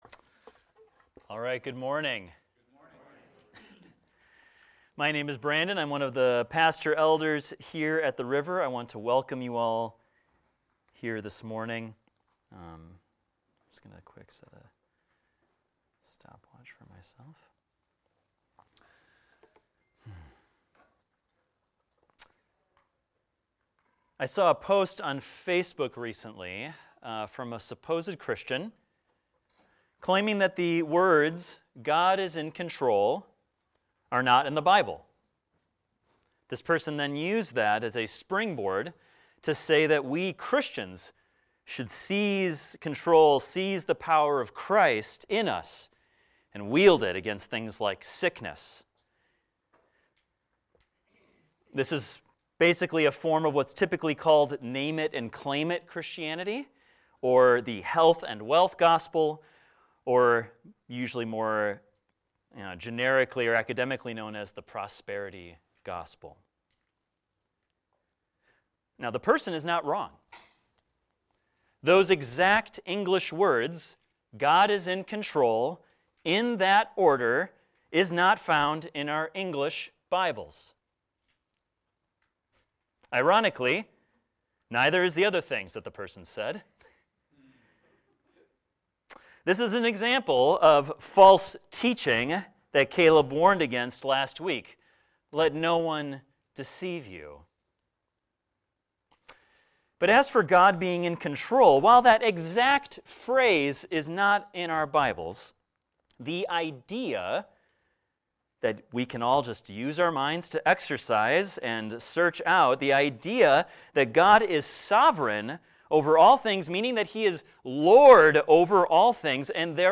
This is a recording of a sermon titled, "Jesus Wins."